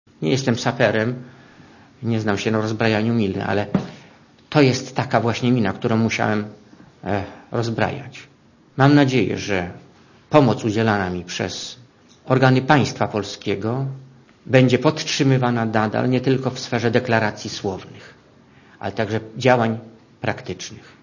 Posłuchaj komentarza Leona Kieresa